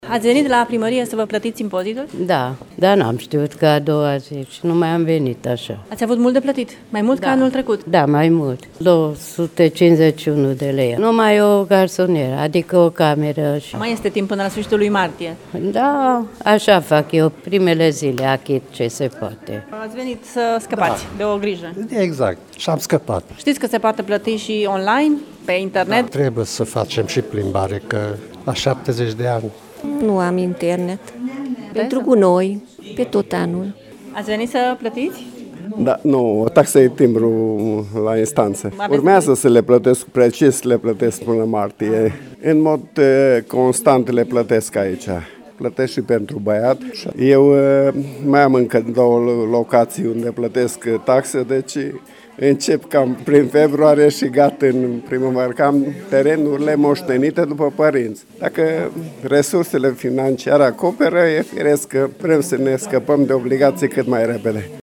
Pensionarii mureșeni s-au grăbit să plătească în primele zile pentru a scăpa de o grijă: